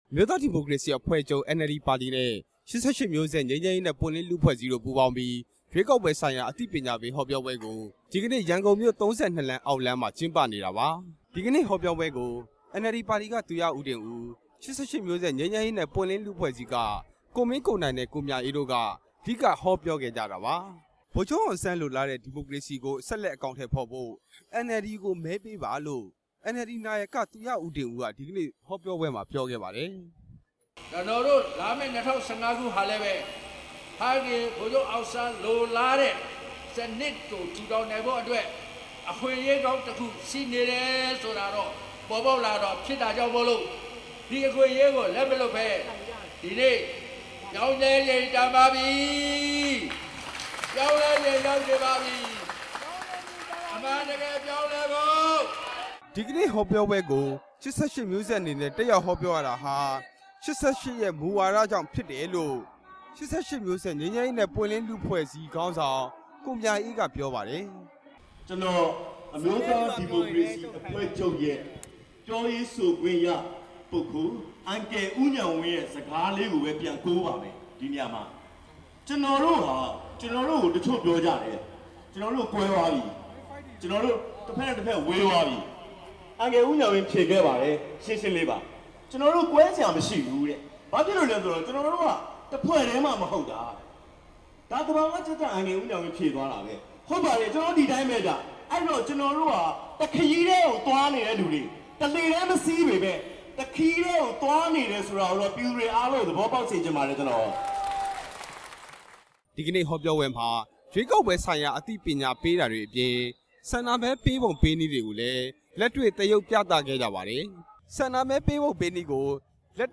အမျိုးသားဒီမိုကရေစီအဖွဲ့ချုပ်နဲ့ ၈၈ မျိုးဆက်ငြိမ်းချမ်းရေးနဲ့ပွင့်လင်းလူ့အဖွဲ့အစည်းတို့ ပူးတွဲ ကျင်းပတဲ့ ရွေးကောက်ပွဲဆိုင်ရာ အသိပညာပေး ဟောပြောပွဲကို ရန်ကုန်မြို့ ၃၂ လမ်းမှာ ကျင်းပခဲ့ပါတယ်။